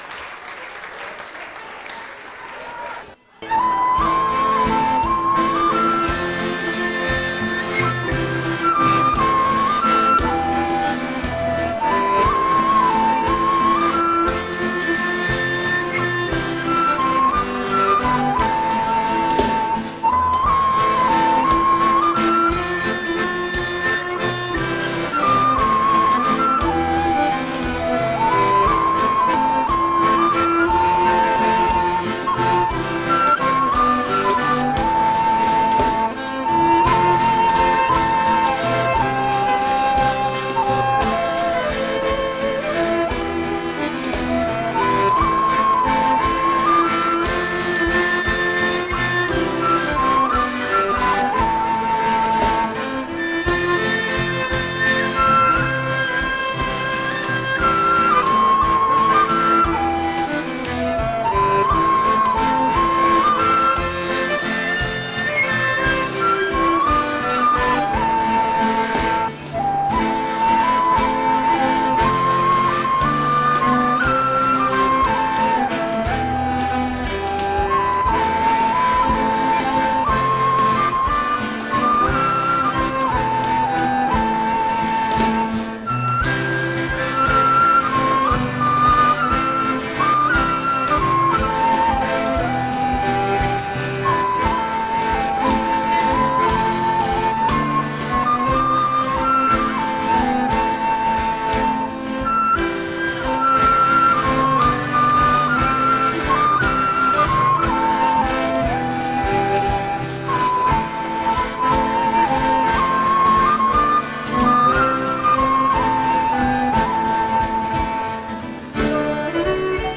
Voici une version du morceau enregistrée au cours d'un bal. Il est suivi de deux autres thèmes traditionnels (sans titre à ma connaissance)..